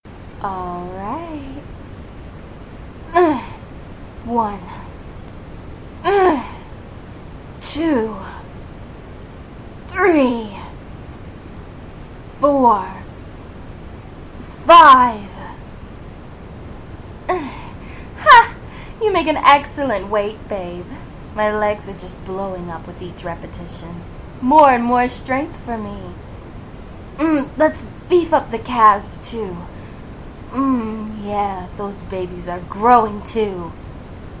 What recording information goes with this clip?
Please note, I cannot eliminate all of the background noise, but this is an option for those of you with more sensitive ears.